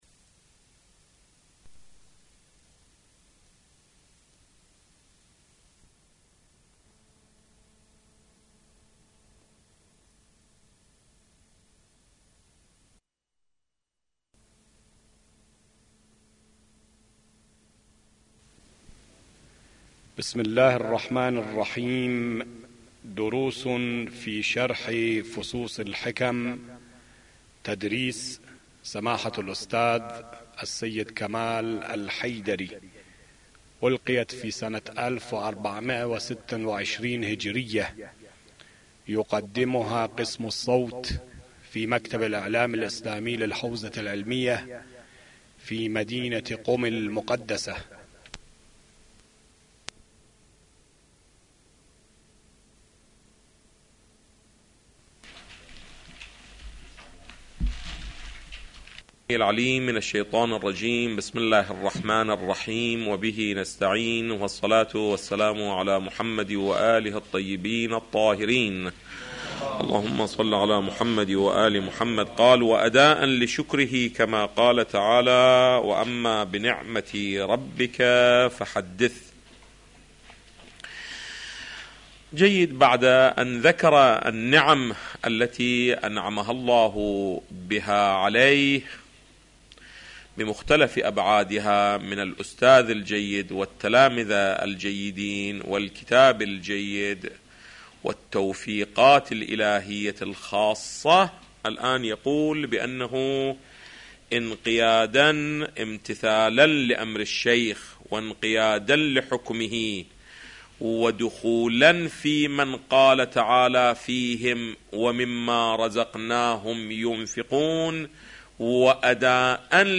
استاد سيد کمال حيدري - فصوص الحکم | مرجع دانلود دروس صوتی حوزه علمیه دفتر تبلیغات اسلامی قم- بیان